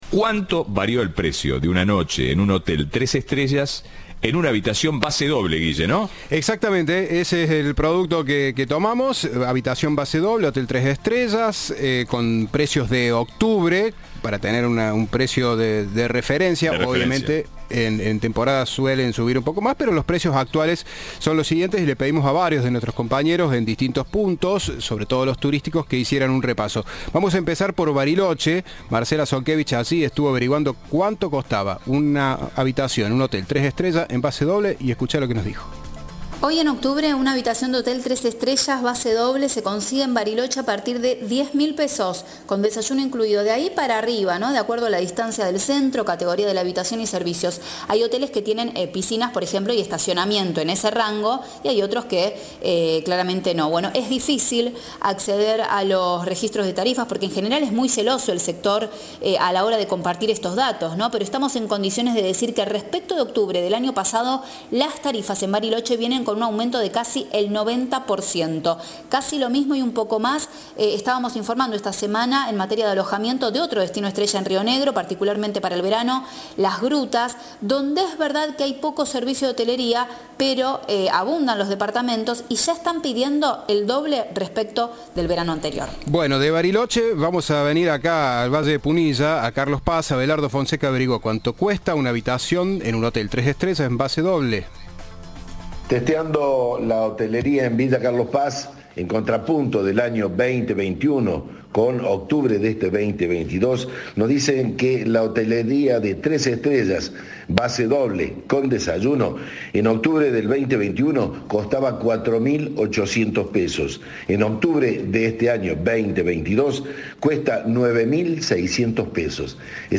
Informe de "Ahora País".